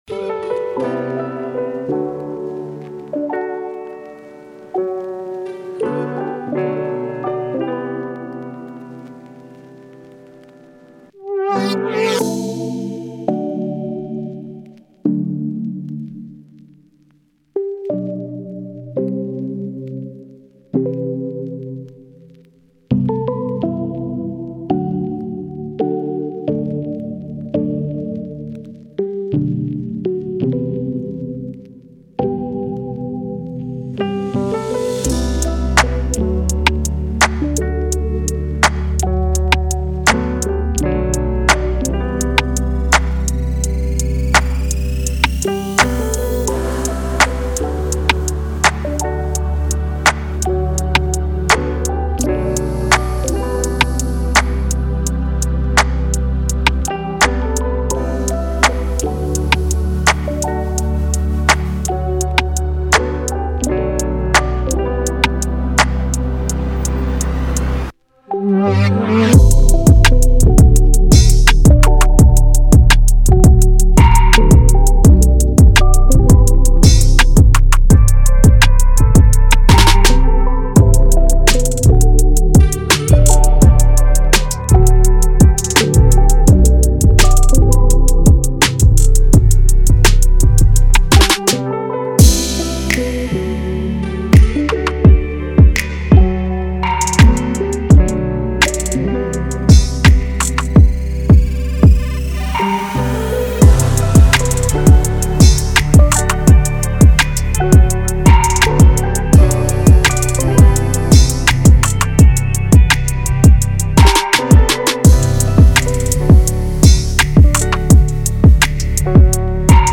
official instrumental
R&B Instrumentals